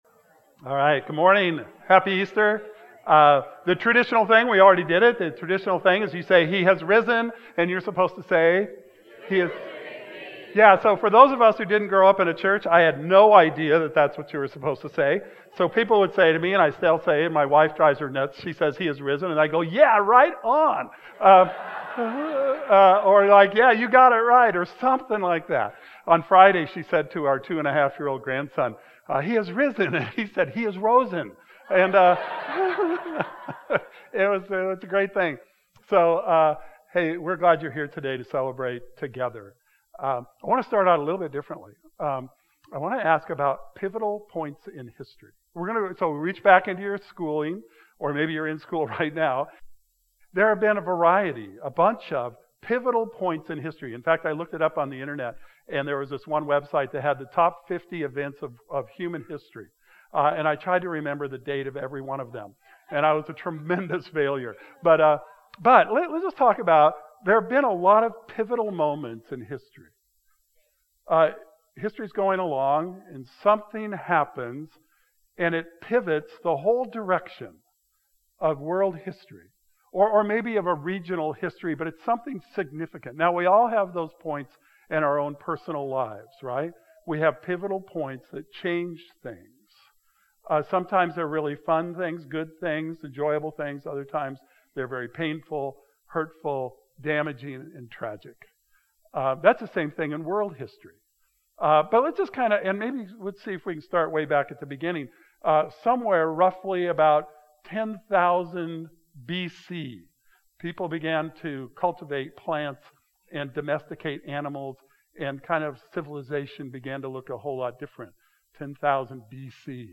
Join us for Easter Sunday service from wherever you are!